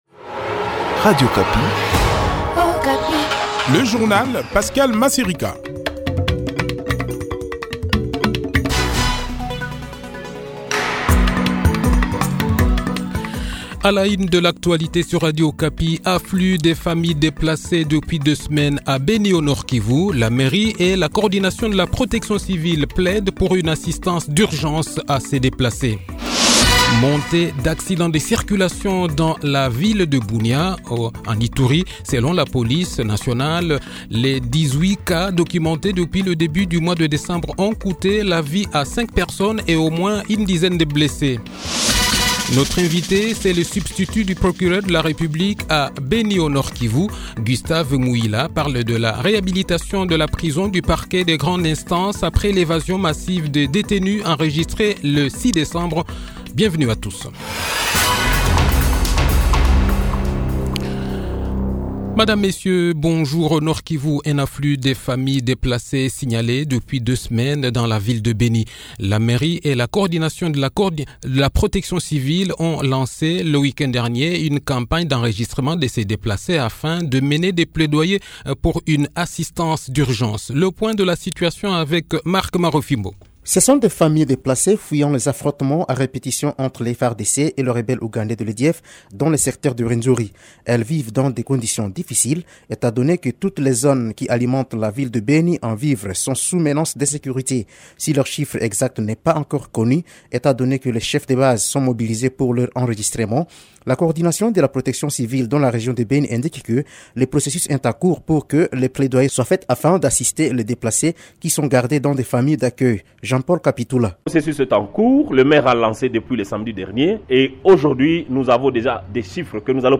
Le journal-Français-Midi